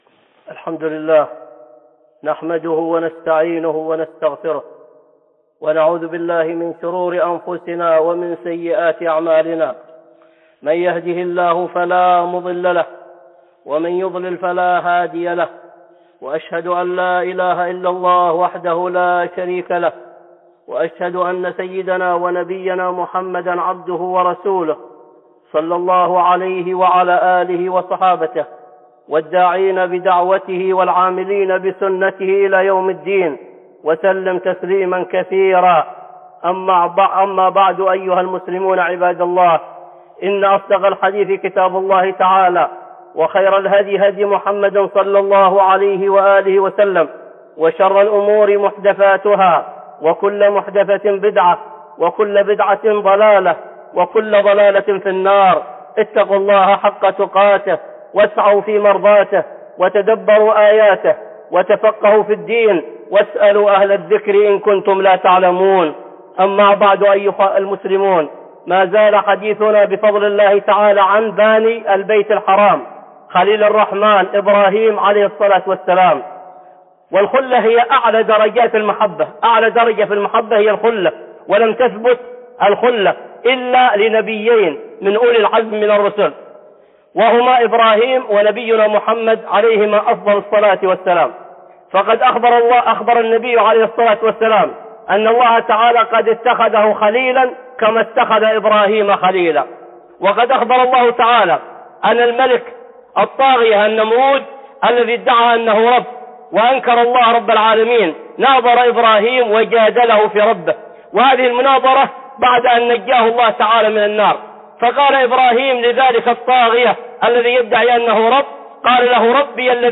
(خطبة جمعة) باني البيت الحرام 2